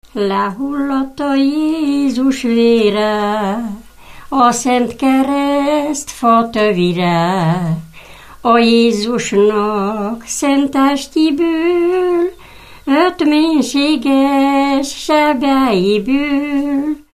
Moldva és Bukovina - Moldva - Szászkút
Műfaj: Húsvéti ének
Stílus: 2. Ereszkedő dúr dallamok